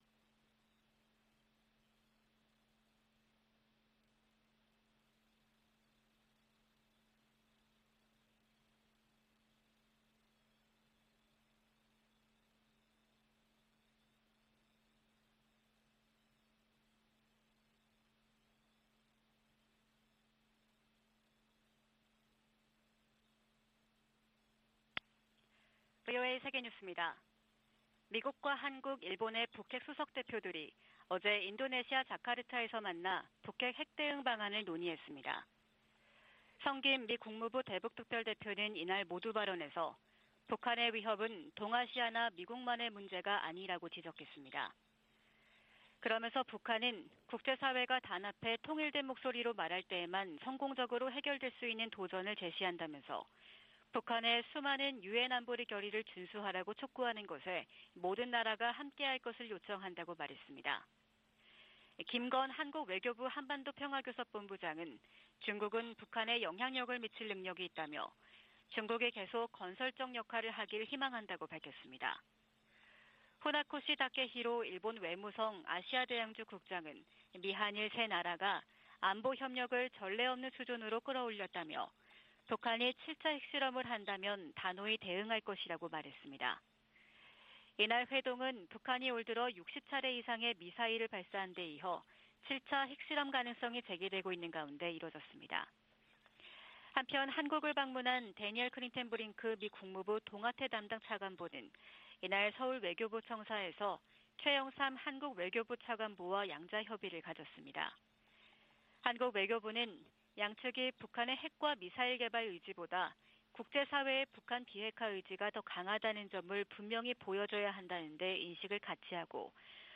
VOA 한국어 '출발 뉴스 쇼', 2022년 12월 14일 방송입니다. 미국과 한국 외교당국 차관보들이 오늘 서울에서 만나 북한 비핵화를 위한 국제사회의 공동 대응을 거듭 강조했습니다. 유럽연합 EU가 북한 김정은 정권의 잇따른 탄도미사일 발사 등에 대응해 북한 국적자 8명과 기관 4곳을 독자 제재 명단에 추가했습니다.